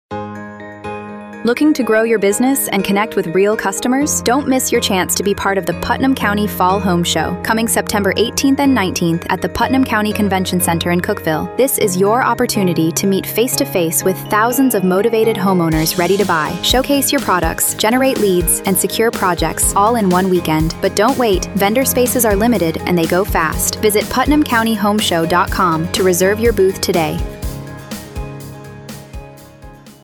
Radio Commercials